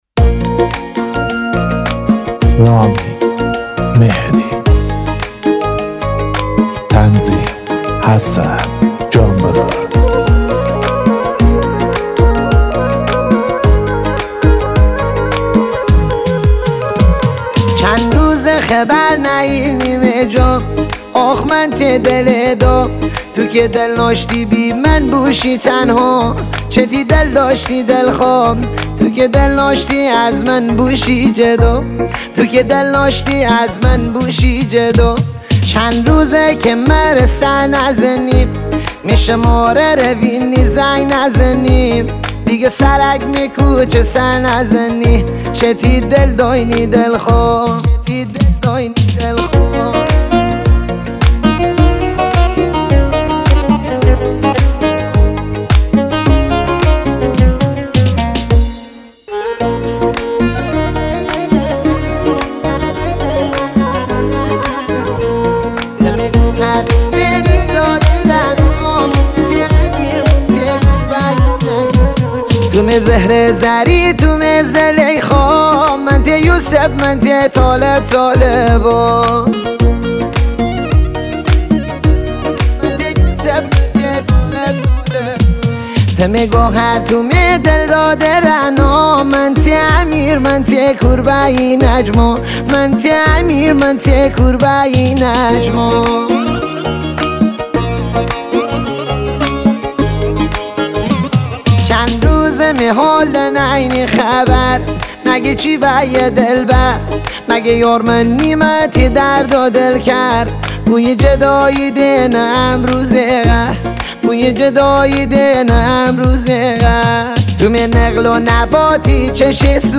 ریتمیک ( تکدست )
ریتمیک ملایم